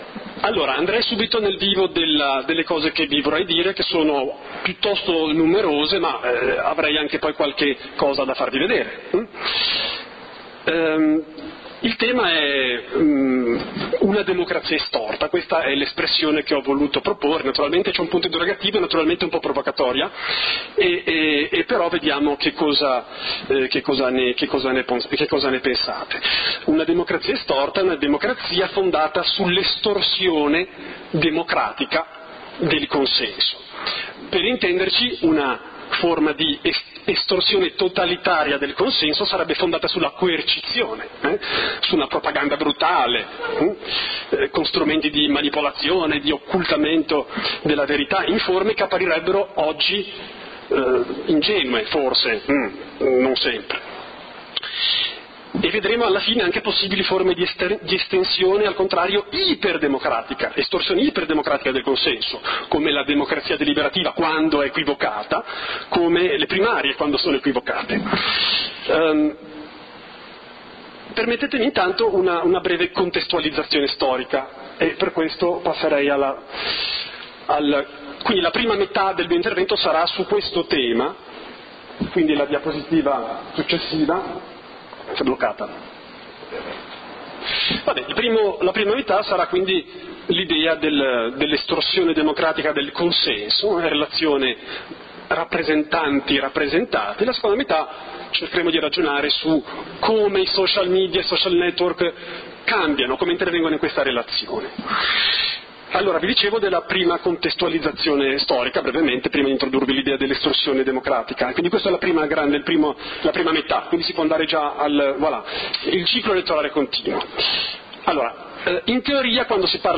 LECTURE / Una democrazia estorta?